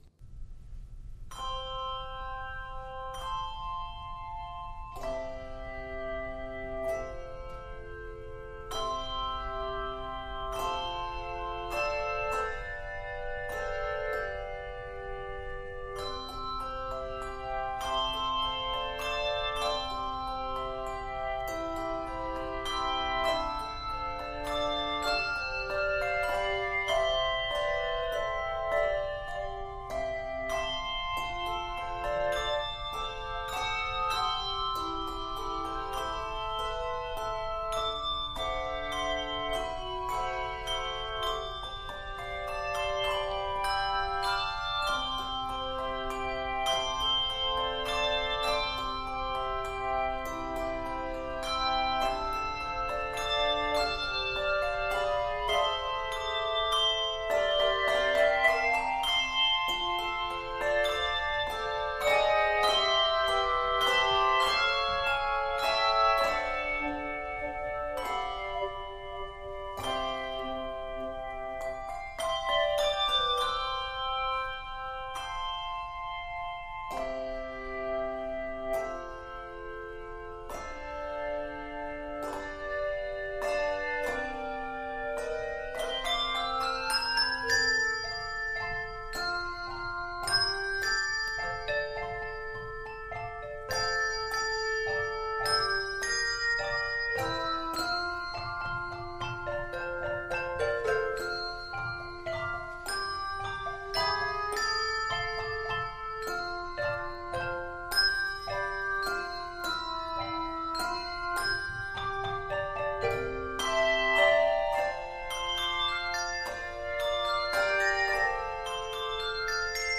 Begins in C Major, then modulates to F major.
Octaves: 3